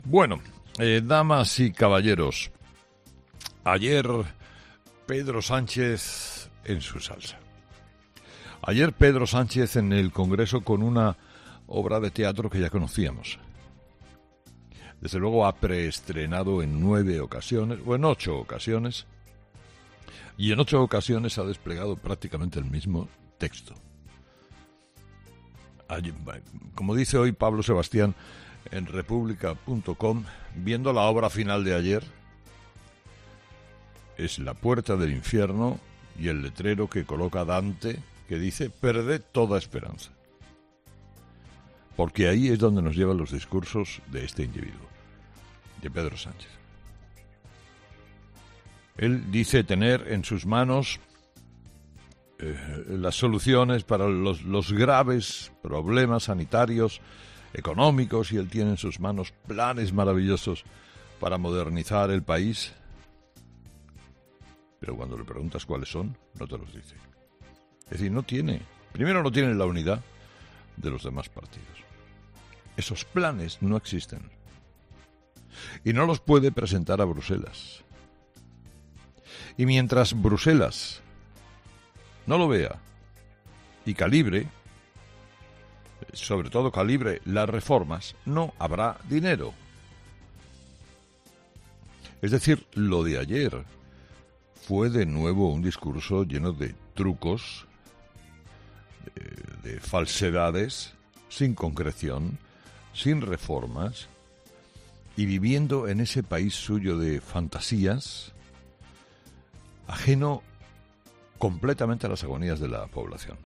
Carlos Herrera, director y presentador de 'Herrera en COPE' ha comenzado el programa de este miércoles analizando las principales claves de la jornada, que este jueves pasa por el calendario de vacunas y la vacuna de Janssen.
En nuestro país, Herrera ha querido destacar en su monólogo, este parón supone un nuevo obstáculo en el plan de vacunación que había puesto en marcha el Gobierno de Sánchez, ya que, como ha querido destacar, "ocho millones de españoles llevan al menos un pinchazo, en el cuerpo, tres llevan los dos".